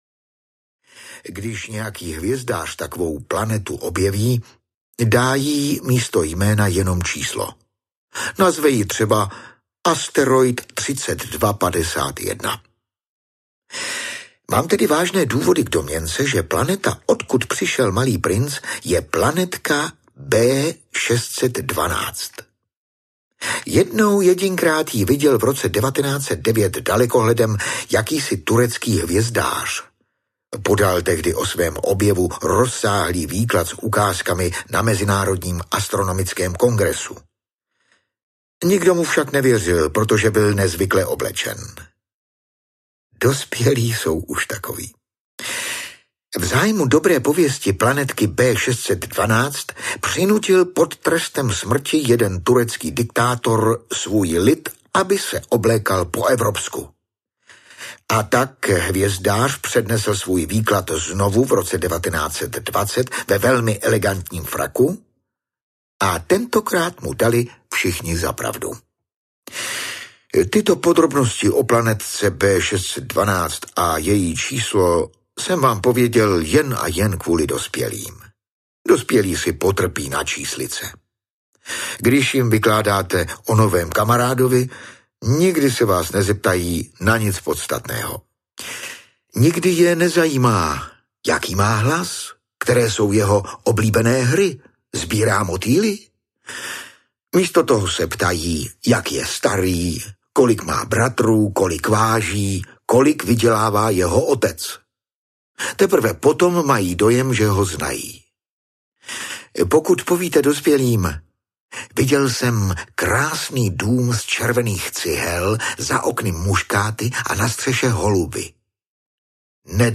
Malý princ audiokniha
Ukázka z knihy
• InterpretDaniela Kolářová, Ladislav Mrkvička